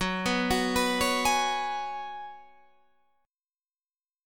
F#sus2sus4 Chord